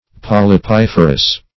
Search Result for " polypiferous" : The Collaborative International Dictionary of English v.0.48: Polypiferous \Pol*y*pif"er*ous\, a. [Polypus + -ferous.]